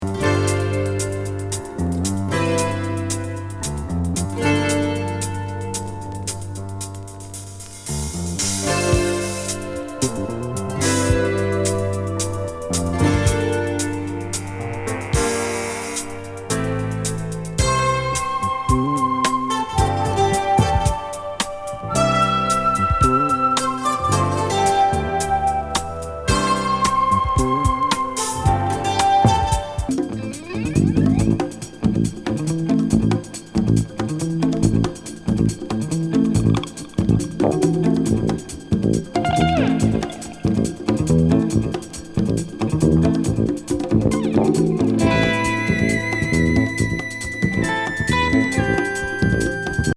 スペーシー・プログレッシブ女体砂丘？フュージョン